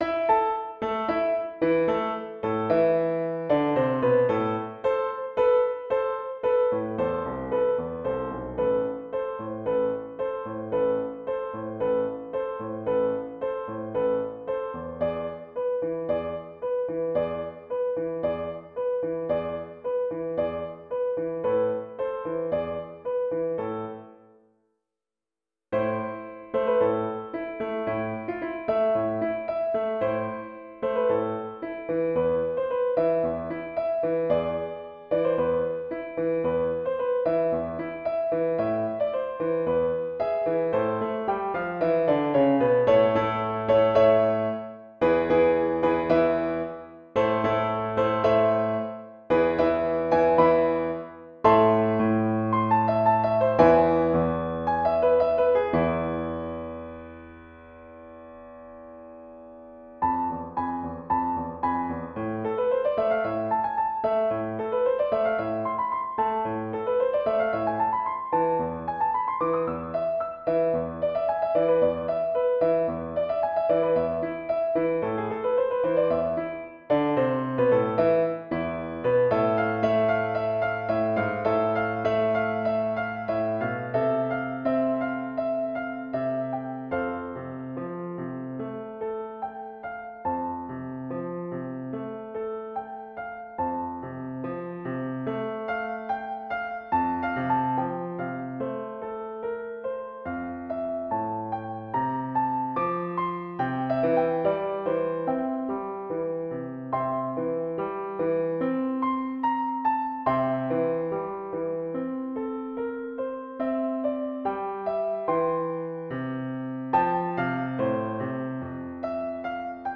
Audio: Piano part alone (slower)